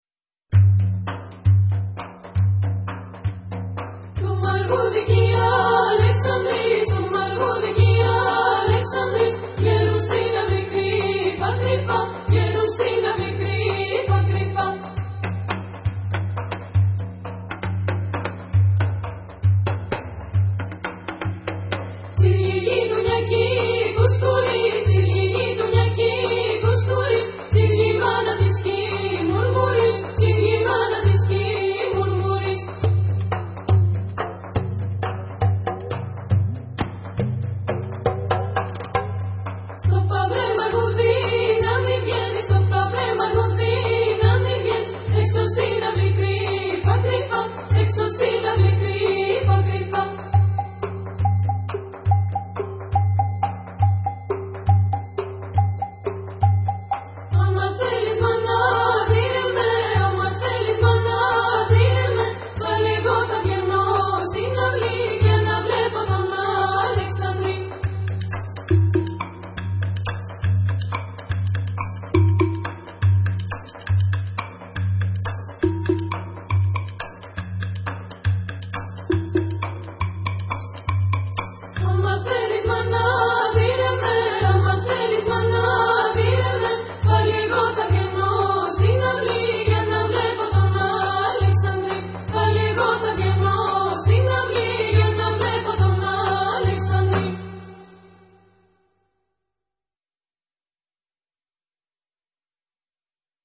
Ce ressemble à un thrène, un chant de deuil traditionnel grec...
ça vient de Grèce.
C'est une collection de CD "The Greek Folk Instruments", et le volume 4 est consacré aux percussions (krousta ?), avec du chant magnifique sur cette piste. ça s'appelle "Alexandris".